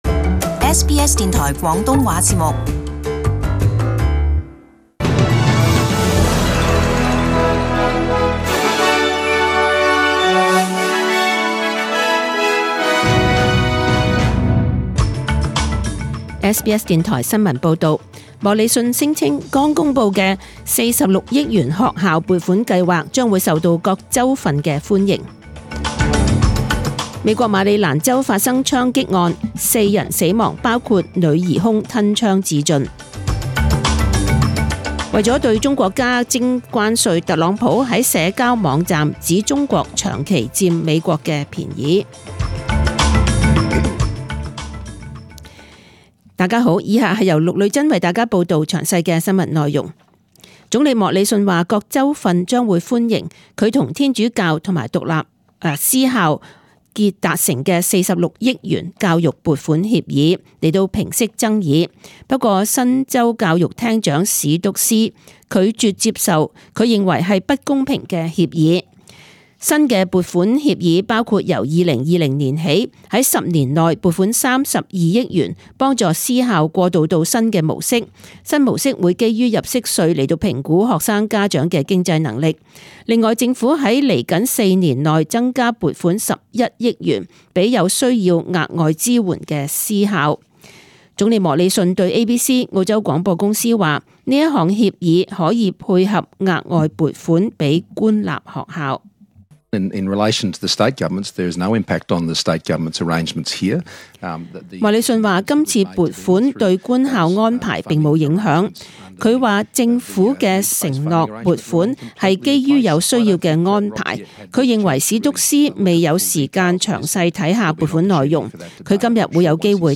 SBS中文新闻 （九月二十一日）
请收听本台为大家准备的详尽早晨新闻。